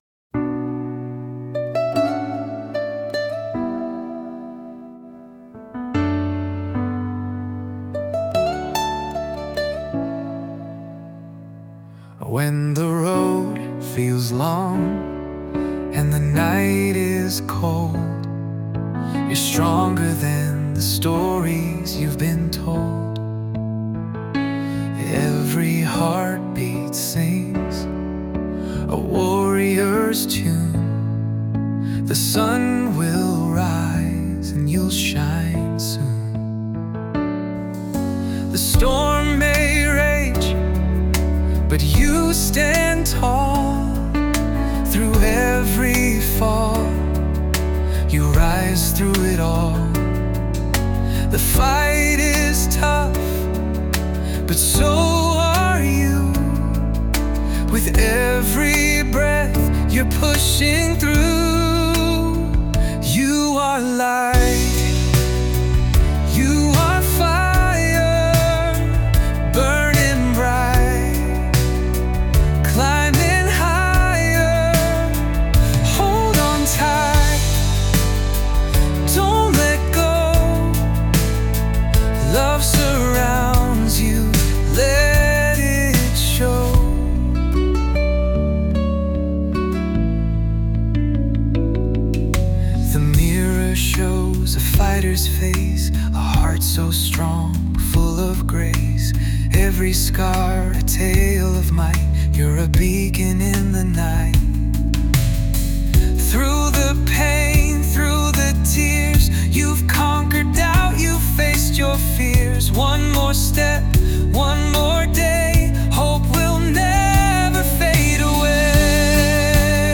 I selected some parameters and a short narrative of what the song was about and Songer created the music and performance based on my lyrics.